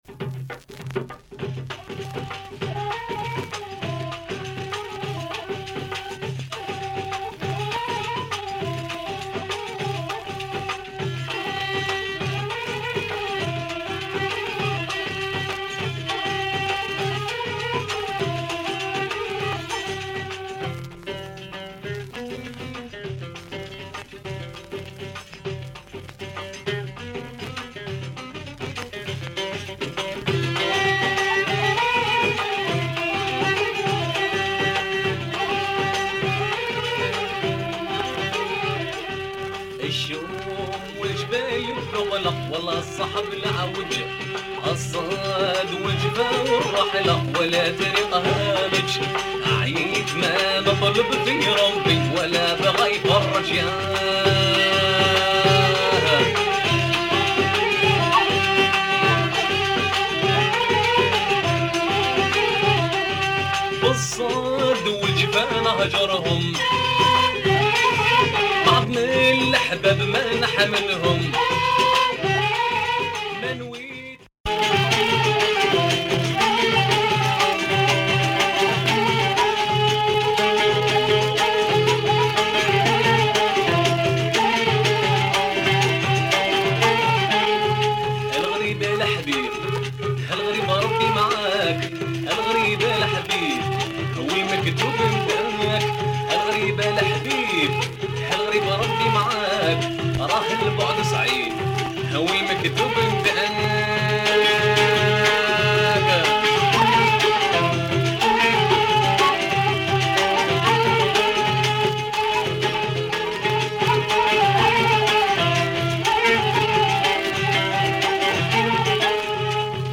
Very rai proto rai, disc in excellent condition.